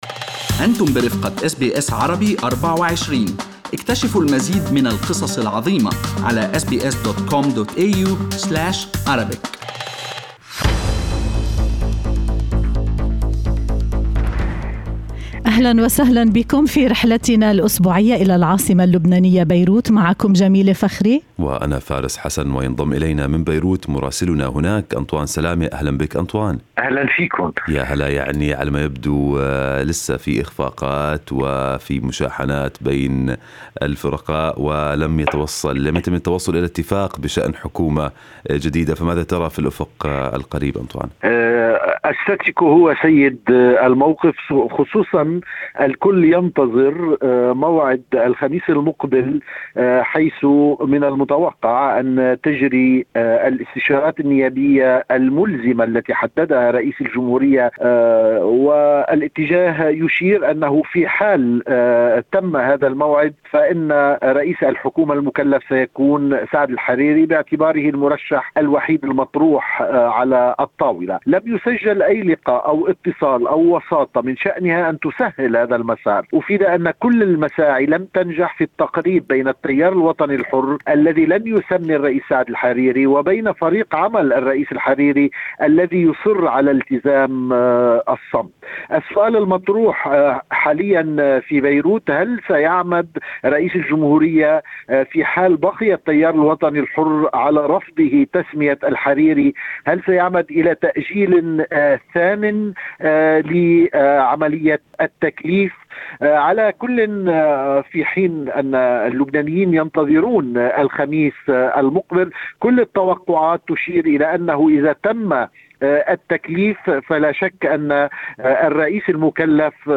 يمكنكم الاستماع إلى تقرير مراسلنا في بيروت بالضغط على التسجيل الصوتي أعلاه.